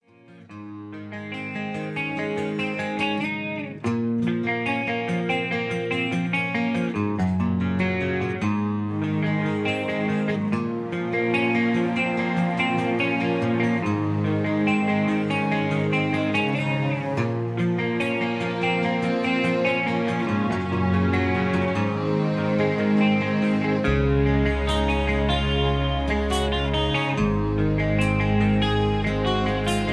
(Version-2, Key-Bbm) Karaoke MP3 Backing Tracks
Just Plain & Simply "GREAT MUSIC" (No Lyrics).